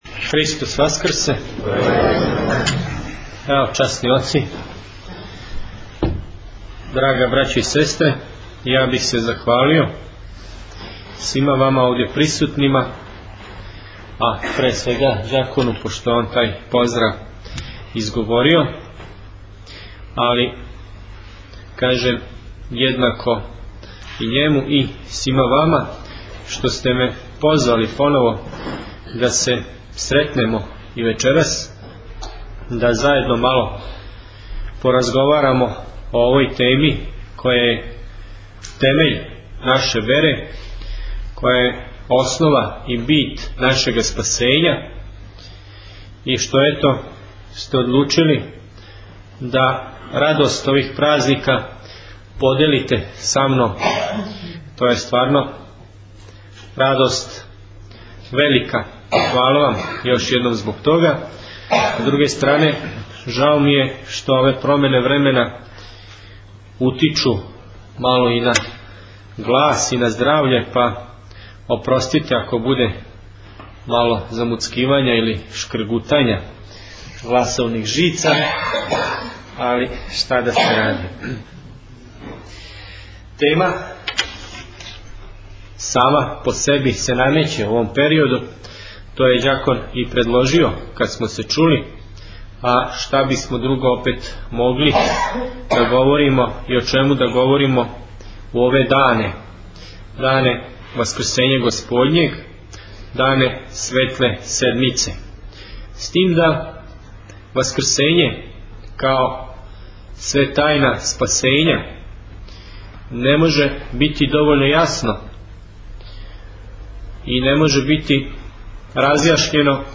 Звучни запис предавања
Бачка Паланка